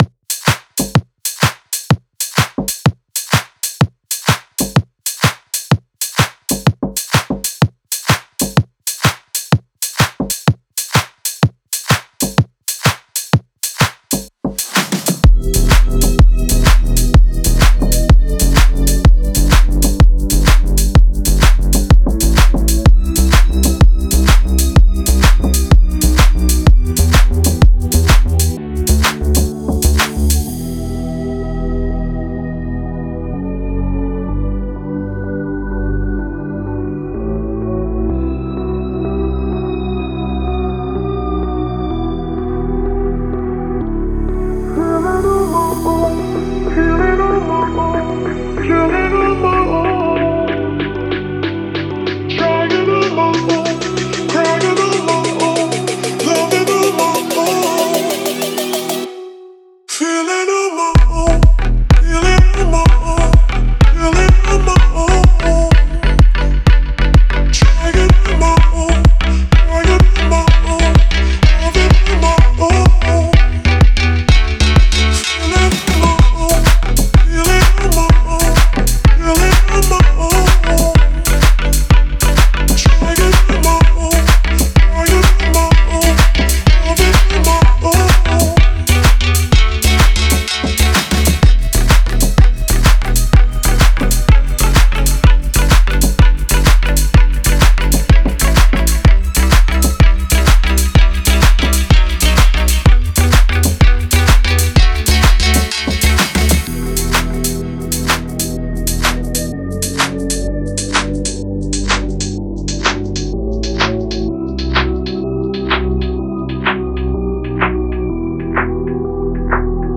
это эмоциональная песня в жанре поп с элементами R&B